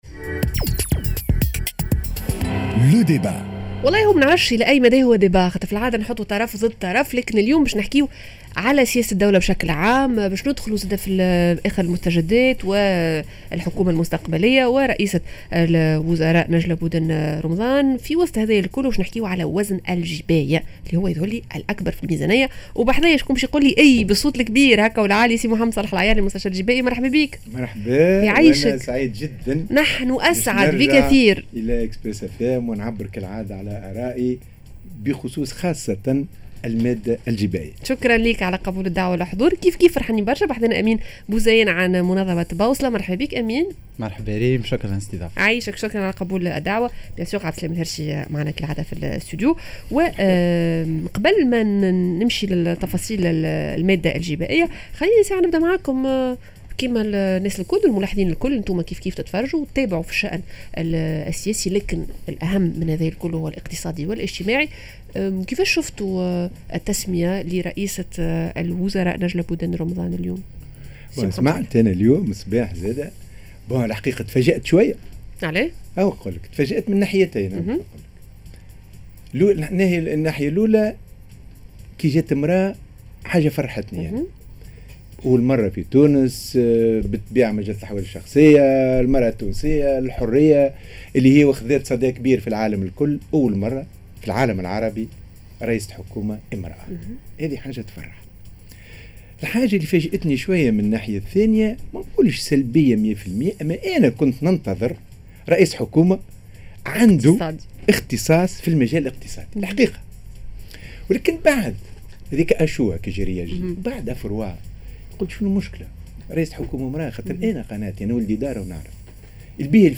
Le débat: علاش انخلصوا برشا ضرائب؟ علاش الضغط الجبائي كبير في تونس؟